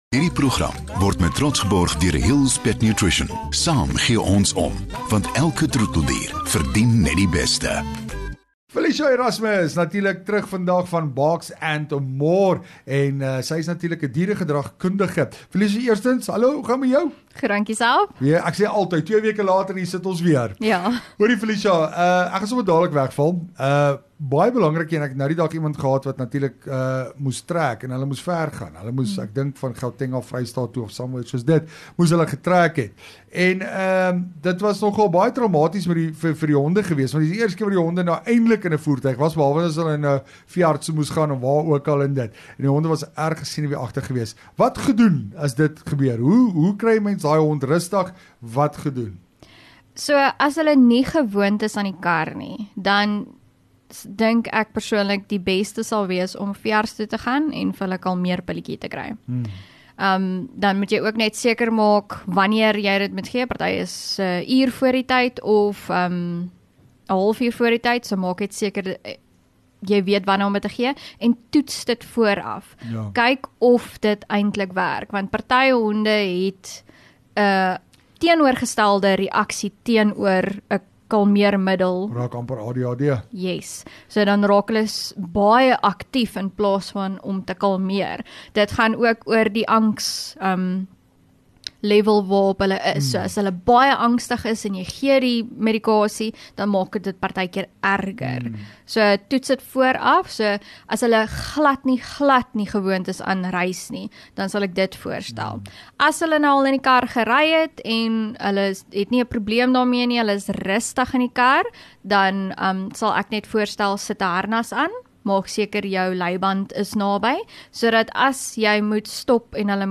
Dieregedragkundige